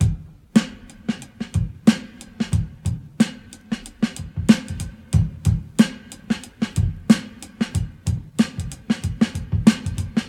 • 92 Bpm Drum Groove G# Key.wav
Free breakbeat - kick tuned to the G# note. Loudest frequency: 724Hz
92-bpm-drum-groove-g-sharp-key-44l.wav